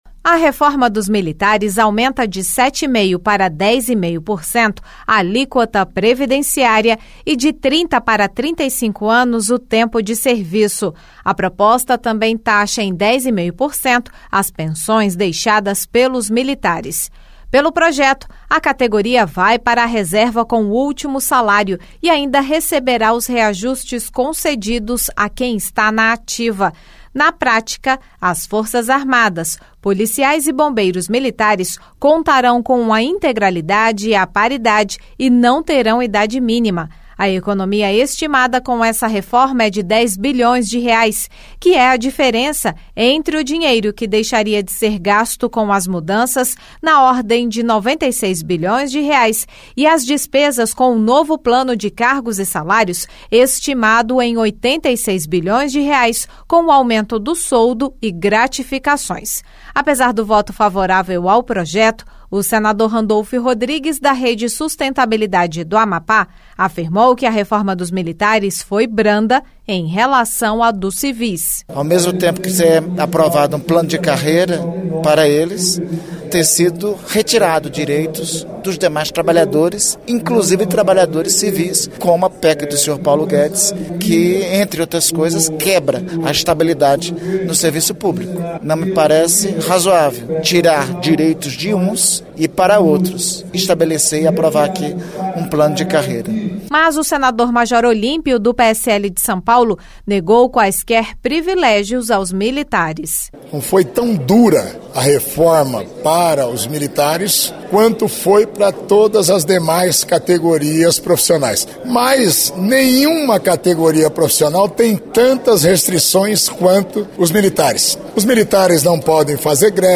O senador Randolfe Rodrigues (Rede-AP) disse que a reforma dos militares foi mais branda em relação à dos civis. Mas o senador Major Olimpio (PSL-SP) negou privilégios às Forças Armadas.